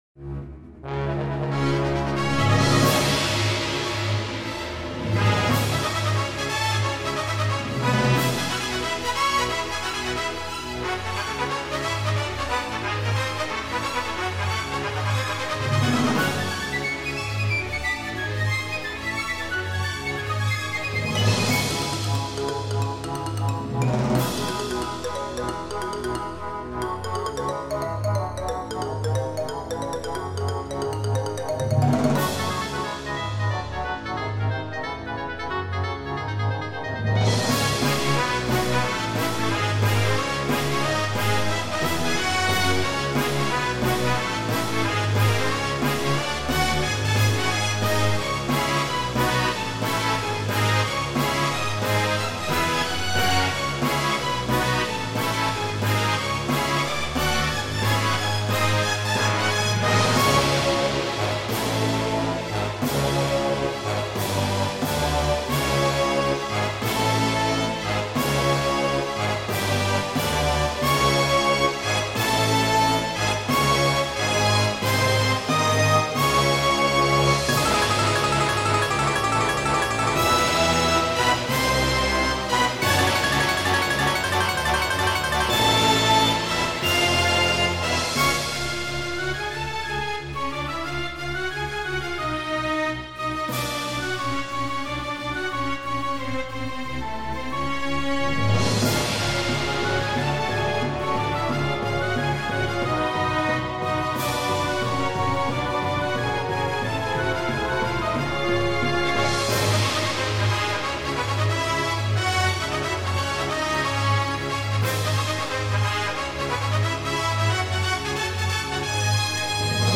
orchestral remix